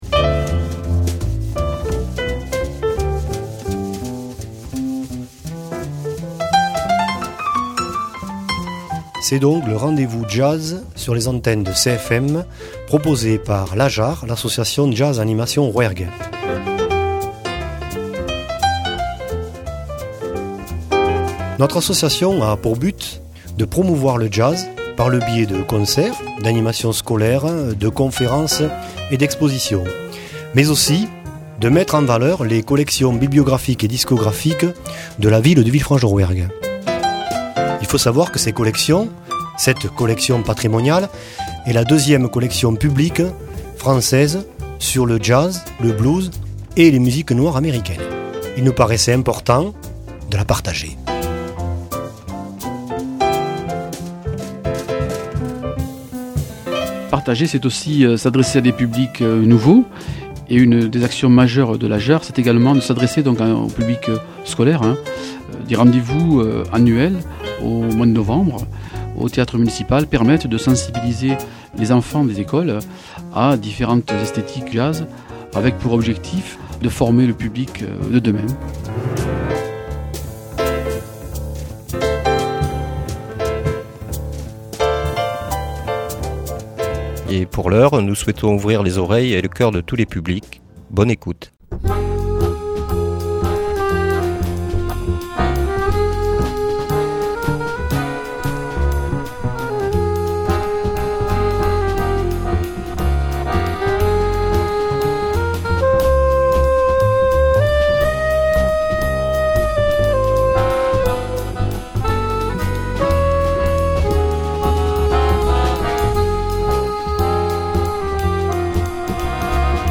Que de beaux noms du Jazz au programme de cette heure!